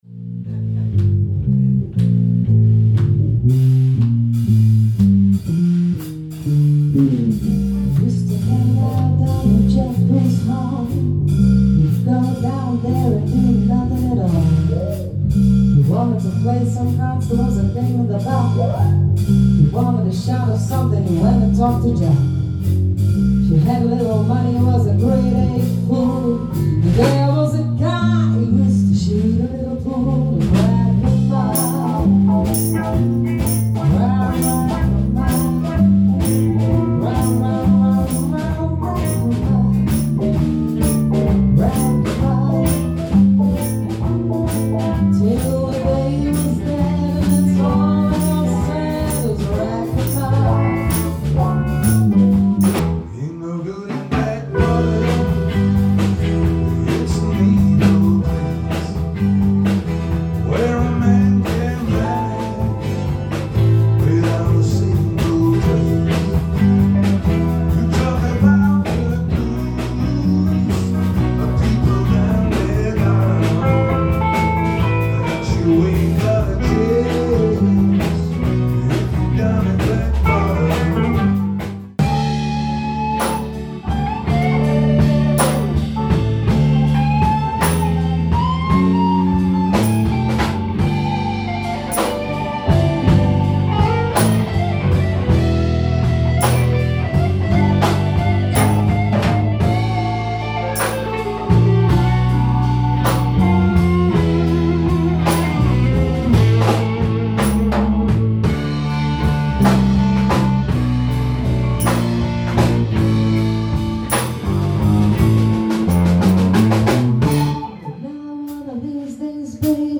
Oefensessie